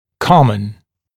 [‘kɔmən][‘комэн]общий, общепринятый, распространённый